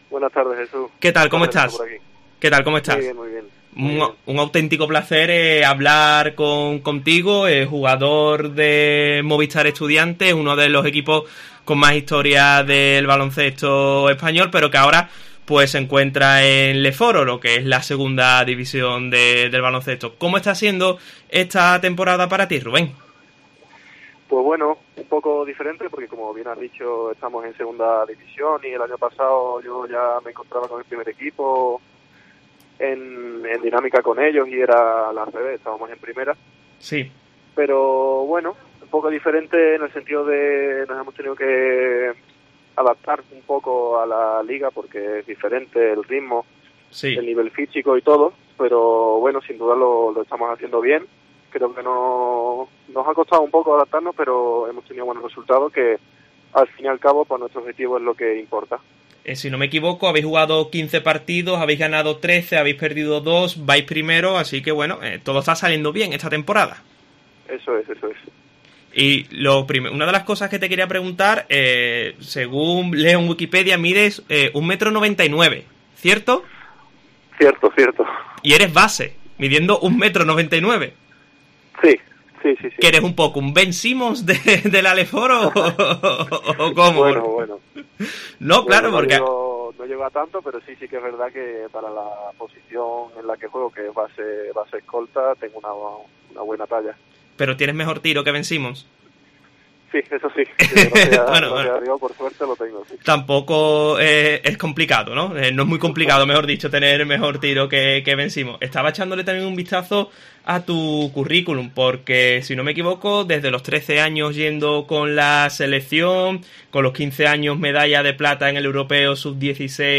La gran promesa del baloncesto gaditano habla en COPE Cádiz sobre su pasado, presente y pone la mirada en el futuro